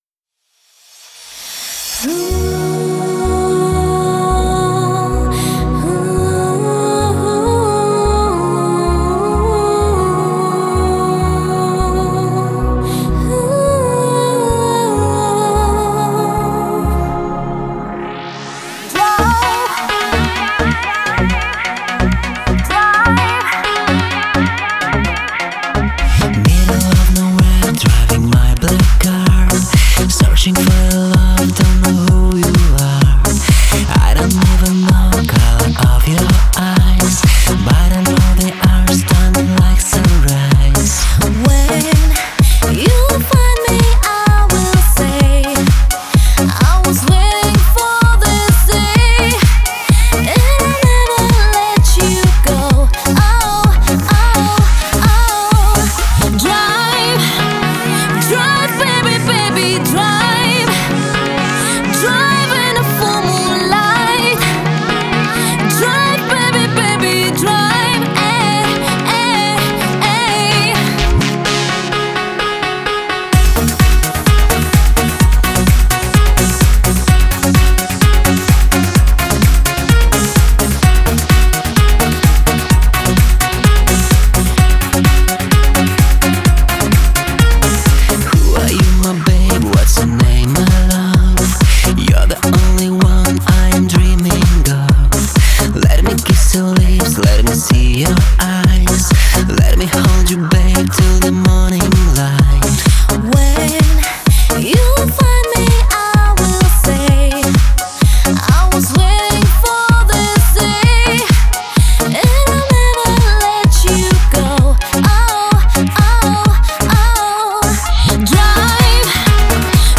это зажигательная танцевальная композиция в жанре Eurodance